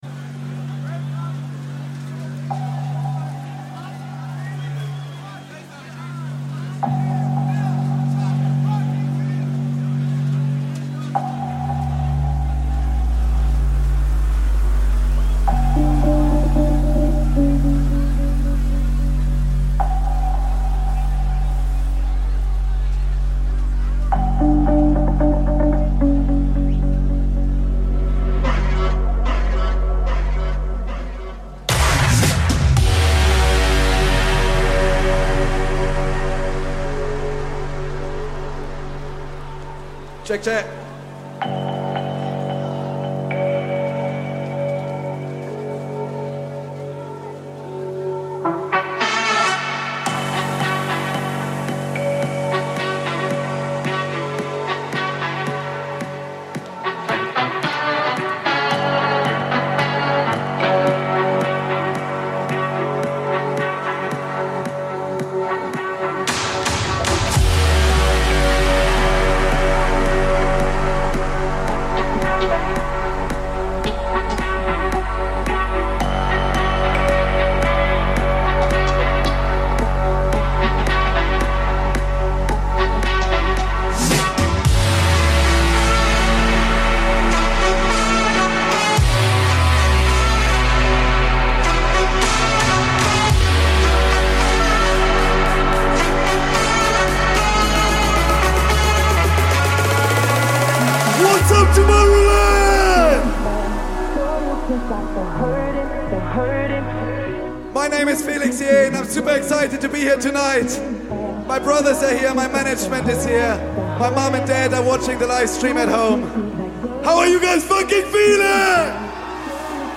Also find other EDM Livesets, DJ
Liveset/DJ mix